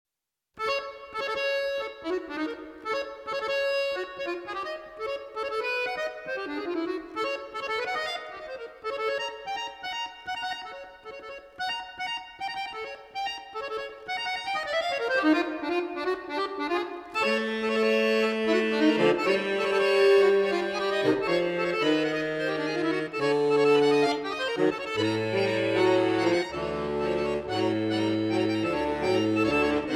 for bayan in 3 movements